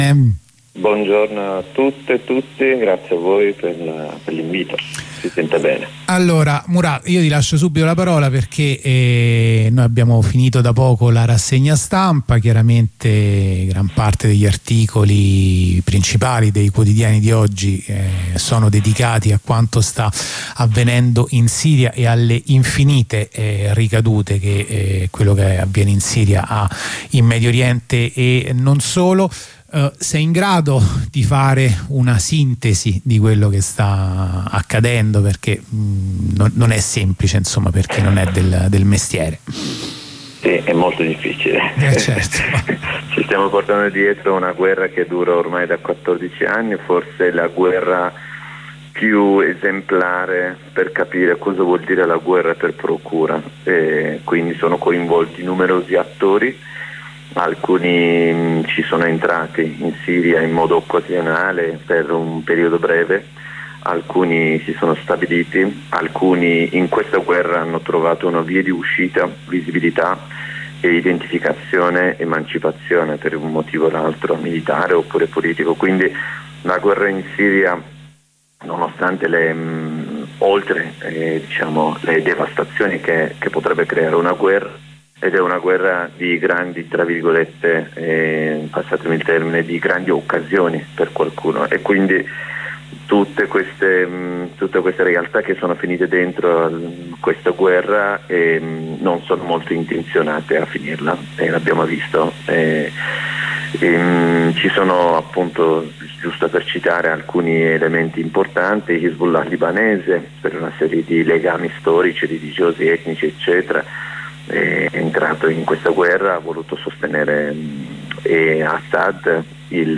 Corrispondenza